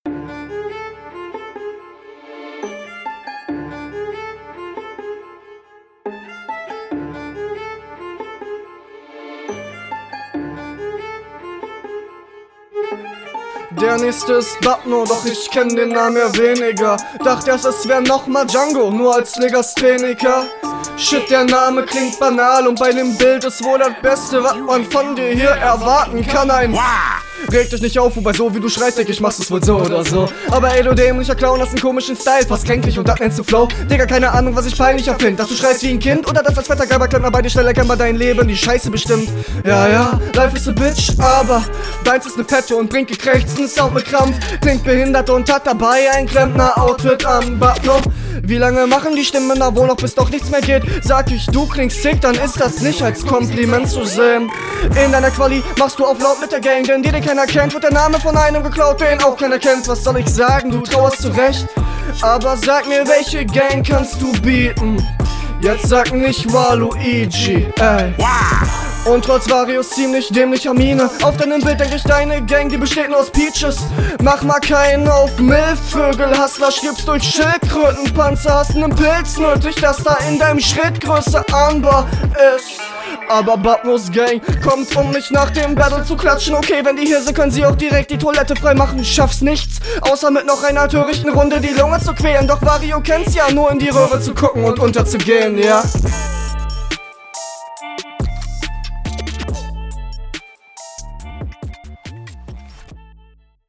Flow: okokok. also flowlich gehst du hier in ne sehr starke Richtung.
Schon der zweite geile Beat, gute Picks von euch beiden.